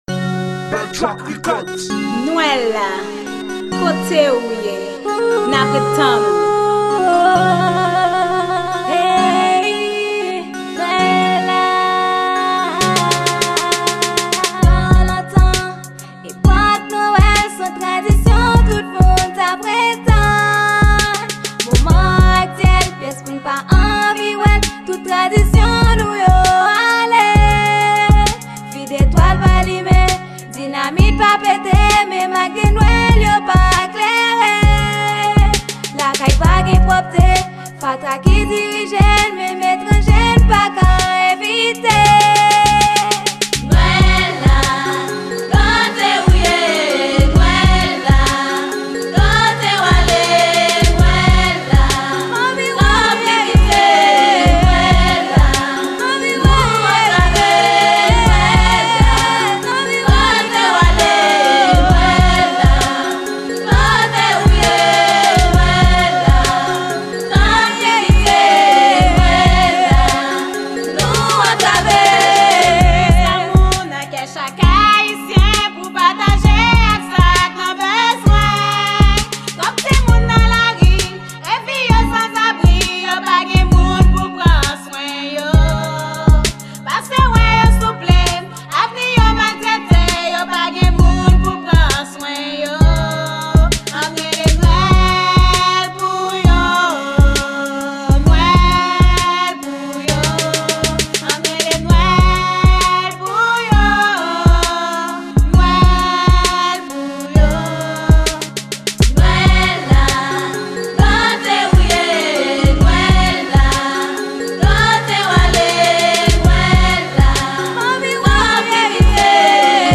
Genre: RNB.